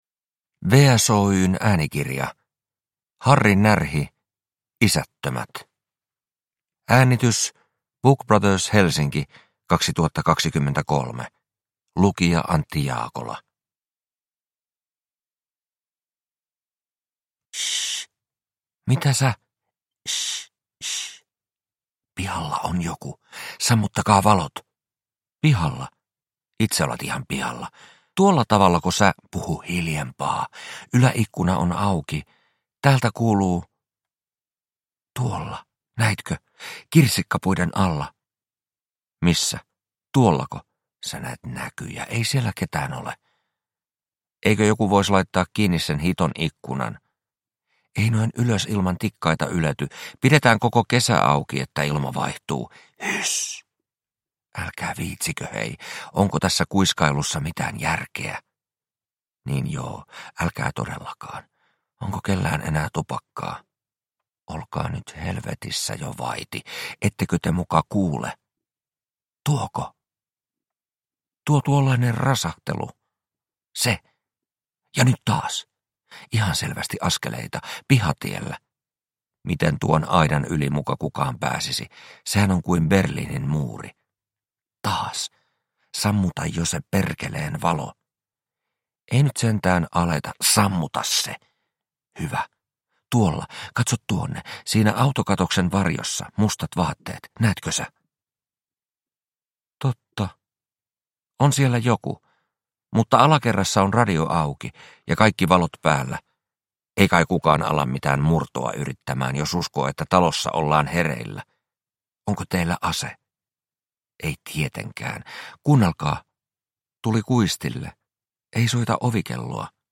Isättömät – Ljudbok – Laddas ner